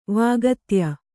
♪ vāgatya